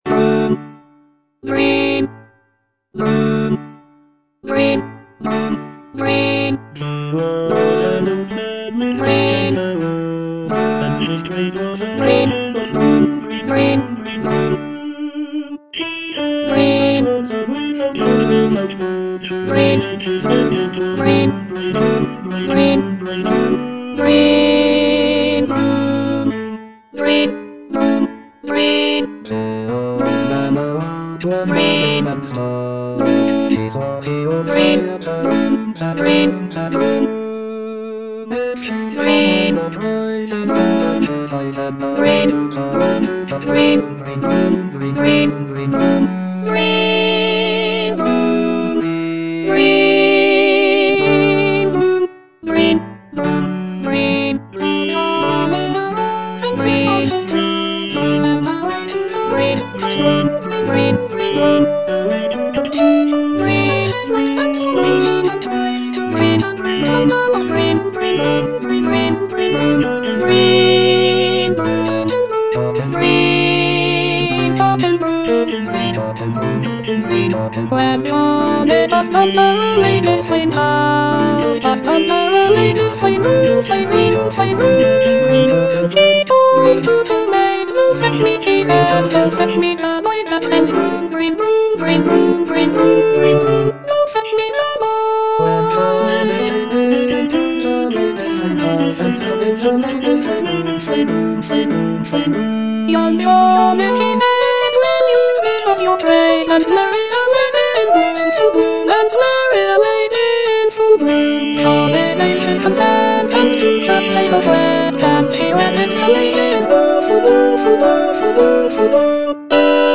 Alto I (Emphasised voice and other voices)